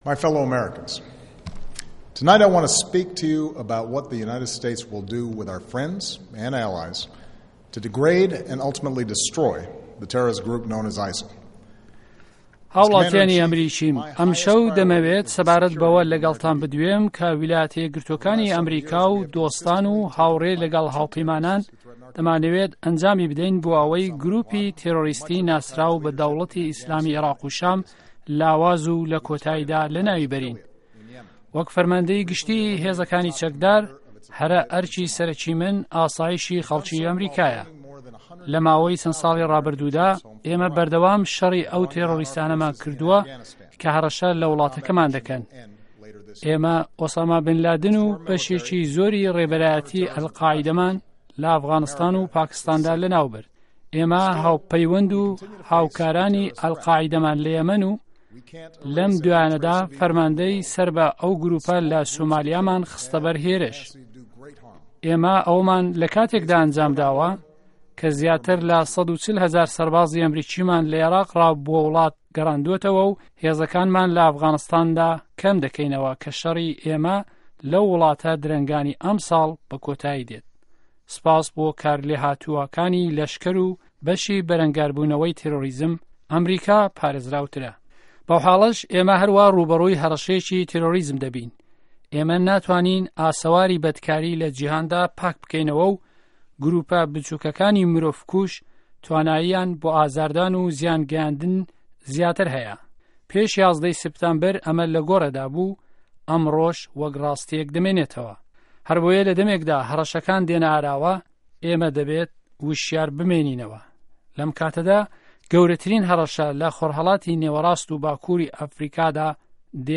وتاری سه‌رۆک به‌ڕاک ئۆباما